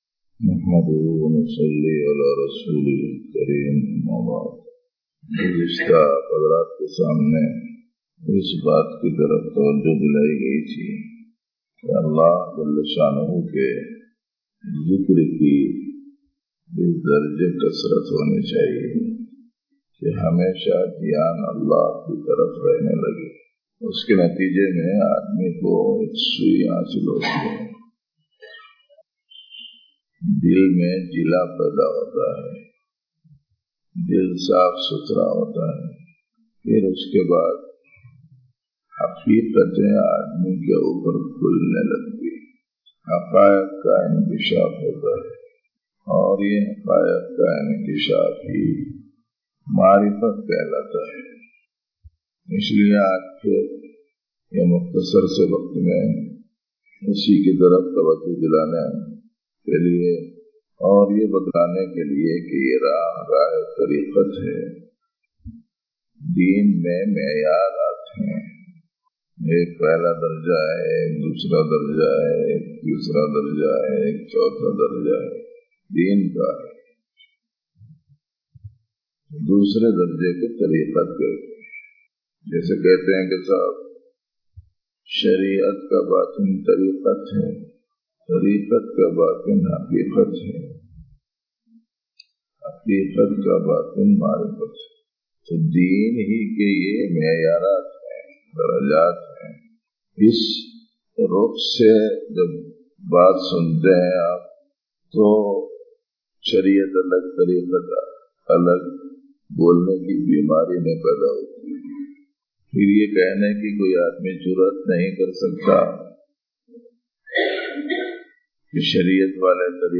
Recorded Date 02-Apr-2015, Khanqah e Jamaliya